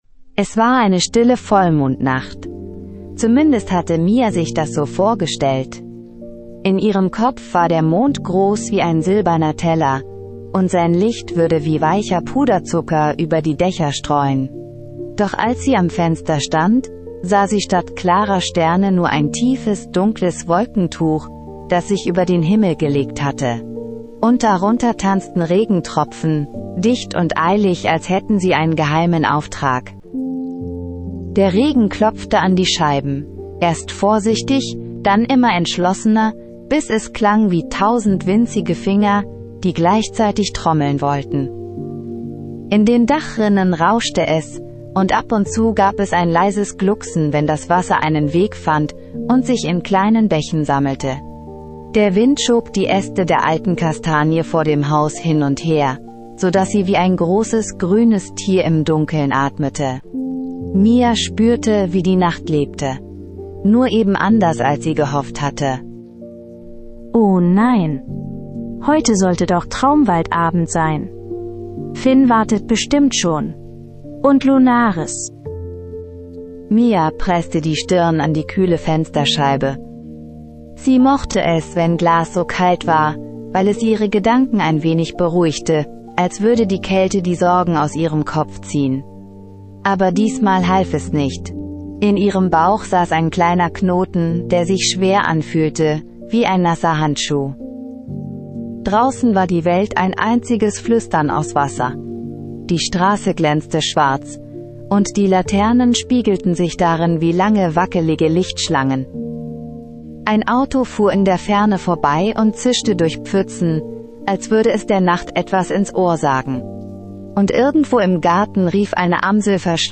Magisches Einschlaf-Hörspiel für Kinder